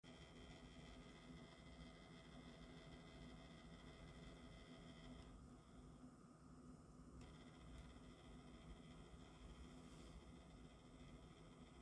Meshify S2 Vibrationen und Störgeräusche
Bei meinem Meshify S2 vibriert das Blechteil, an dem das Mainboard befestigt wird. Bei gewissen Drehzahlen der Grafikkartenlüfter ist dann ein lautes blechernes Rattern zu hören.
Die Aufnahme ist leider sehr leise, aber man hört es.